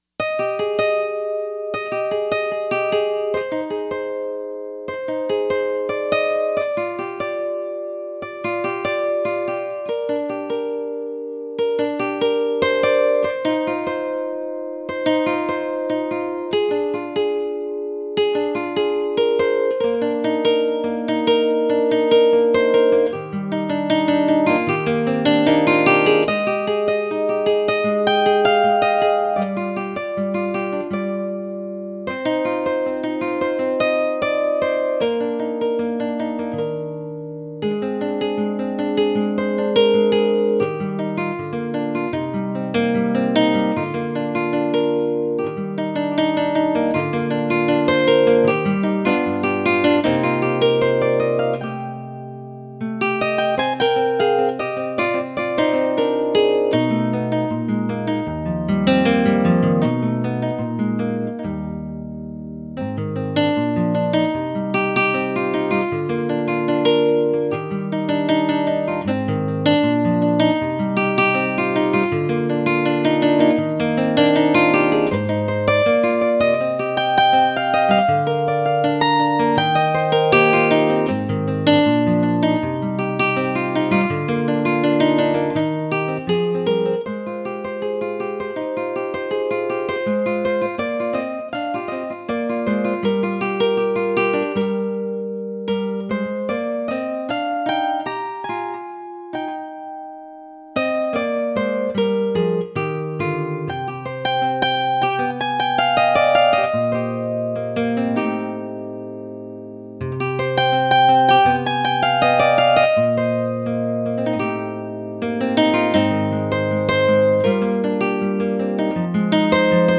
solo on Roland brand synthesizer.